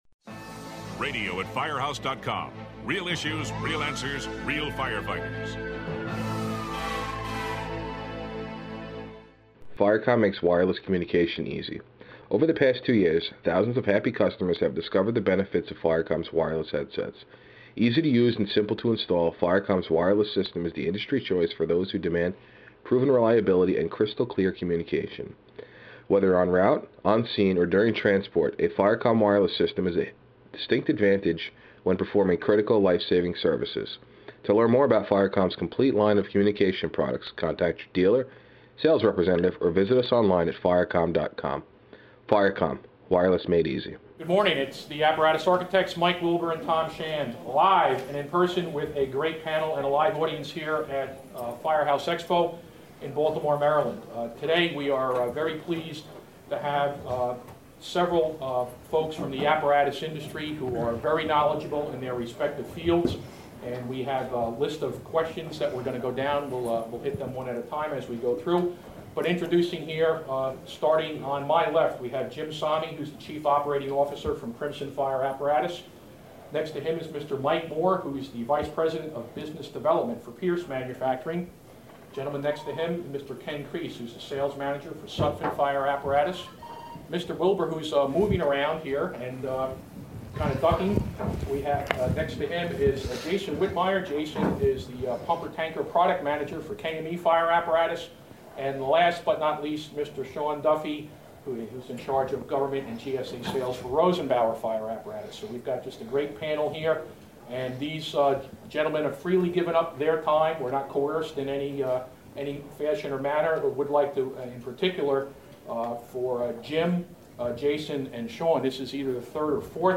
The panel explores the impact service problems with the EPA 2010 compliant engines and also shares how the economy is impacting the fire apparatus industry. The group also answers attendee questions on lease programs and group purchashing options.
This podcast was recorded at Firehouse Expo in July.